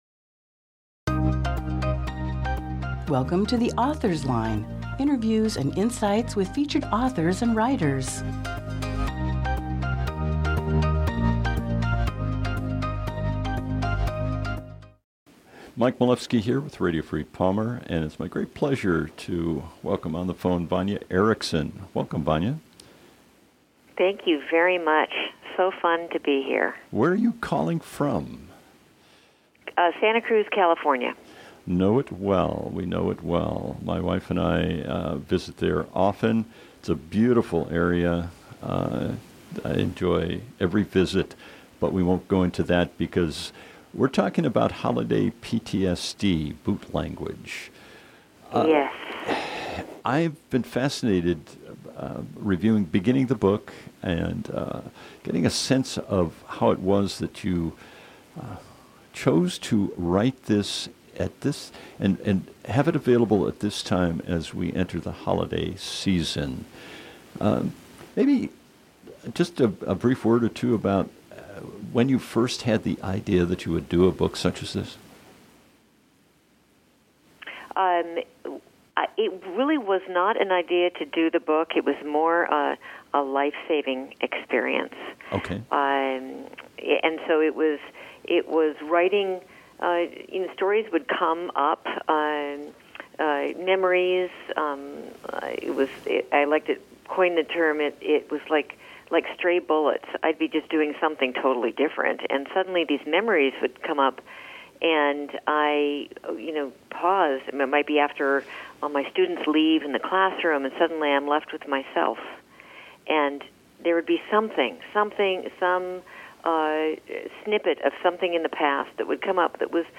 Dec 8, 2018 | Author Interviews